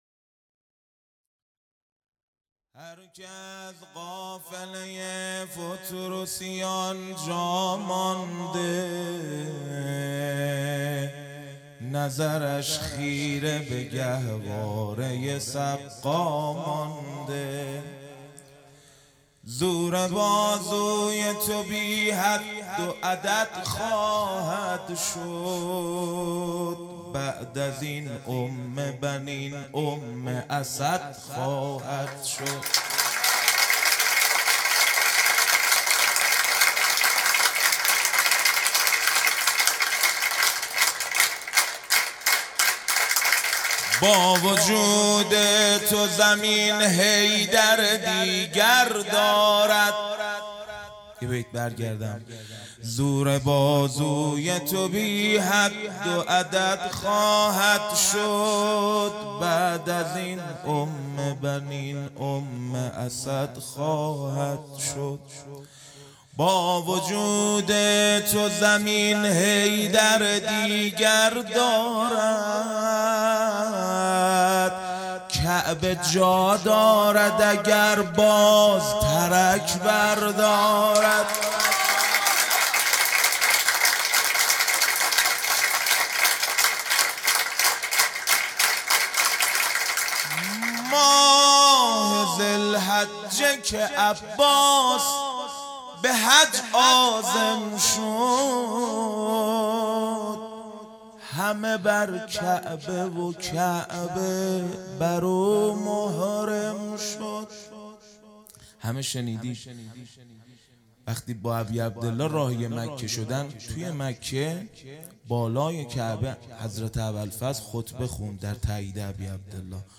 مدح
ولادت سرداران کربلا